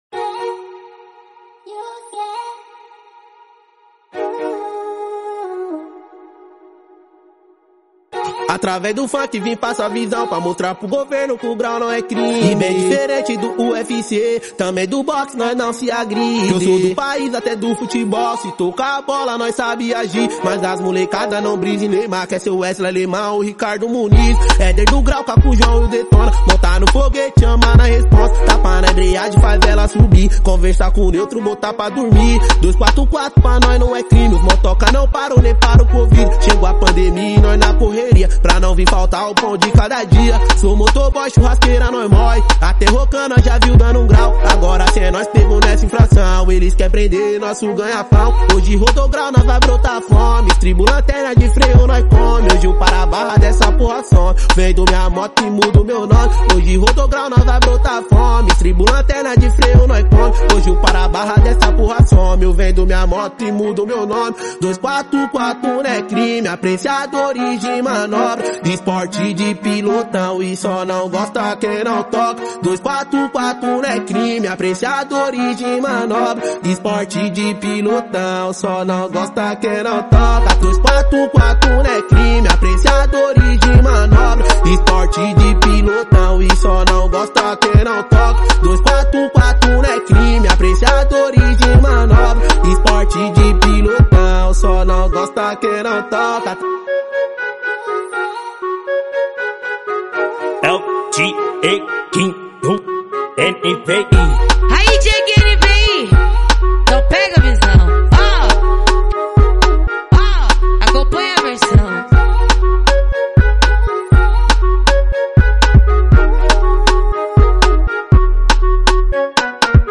2024-04-09 12:01:06 Gênero: Funk Views